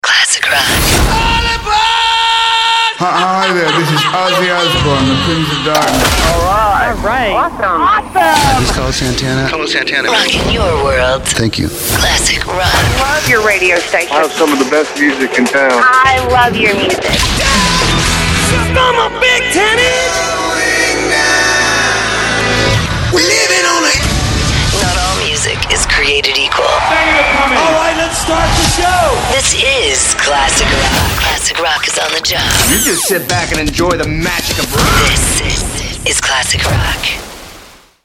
CLASSIC ROCK
Drops, hook sweeps Artists dedicated sweeps and IDs, listener drops, beds and more!